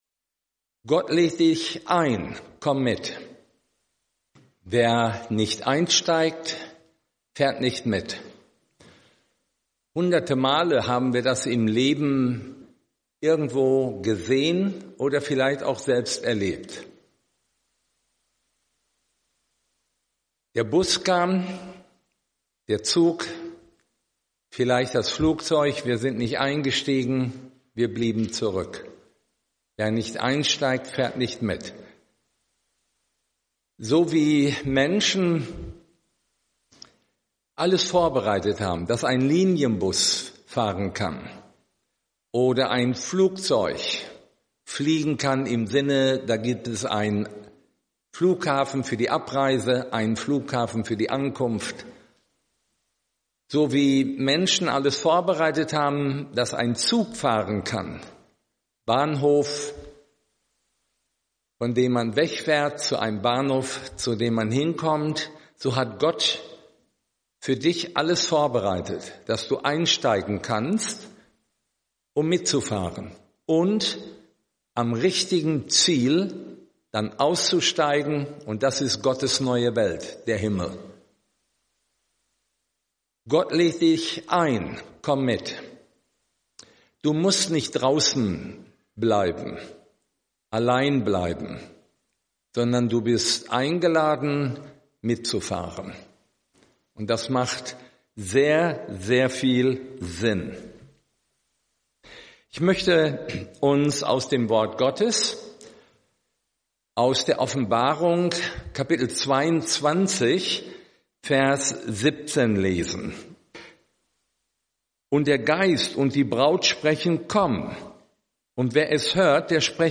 Vortragsreihe